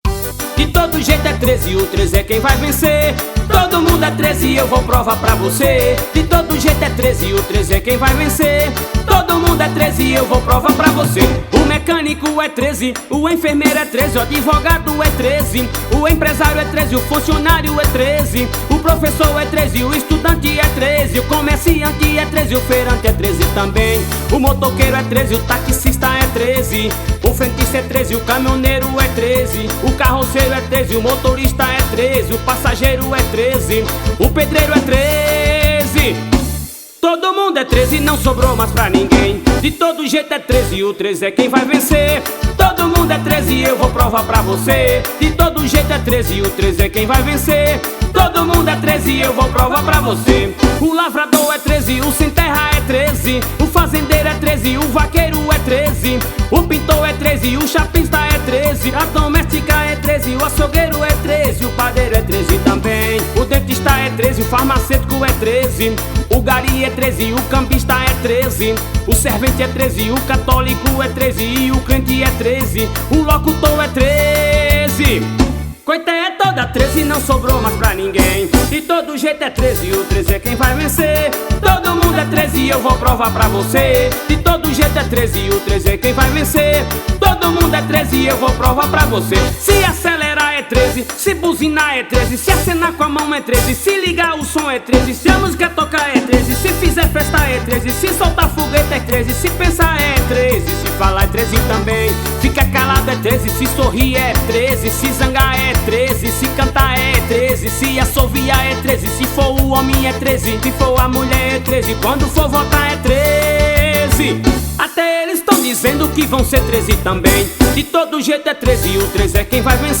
2025-01-06 00:17:56 Gênero: Axé Views